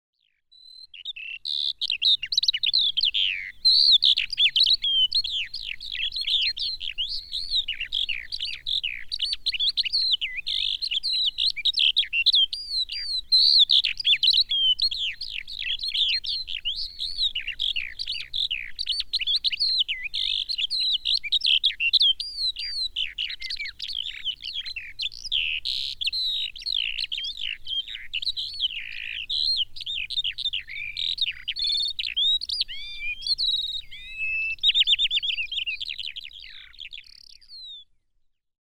Kiuru on karaistunut laji, jonka liverrys alkaa kantautua maaliskuulta alkaen. Kuuntele kiurun ääntä!